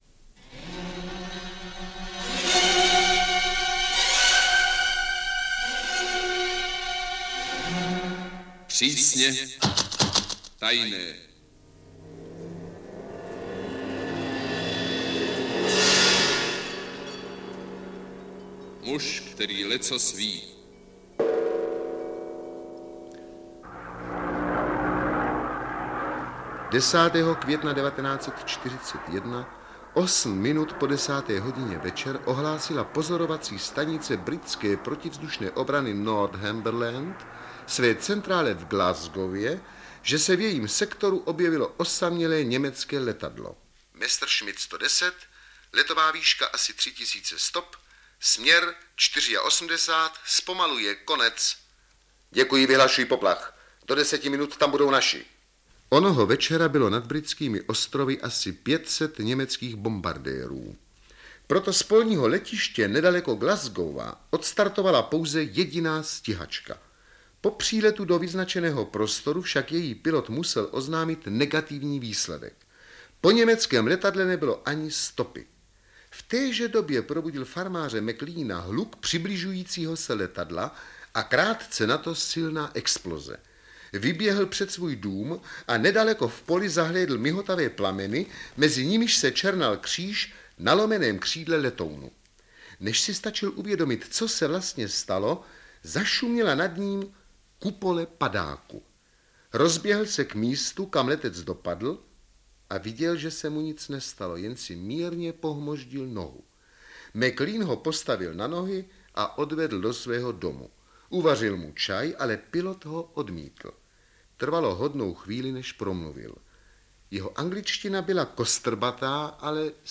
Jeho znělka s přísně tajným razítkem a vzrušujícím hudebním motivem dodávala nedělní siestě nejen trochu napětí, ale i něco humoru,...
AudioKniha ke stažení, 4 x mp3, délka 52 min., velikost 47,0 MB, česky